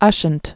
(ŭshənt)